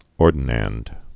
(ôrdn-ănd)